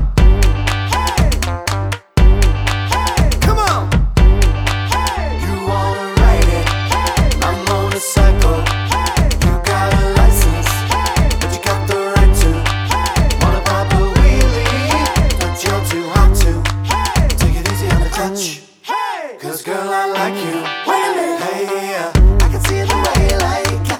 for duet Pop (2010s) 3:22 Buy £1.50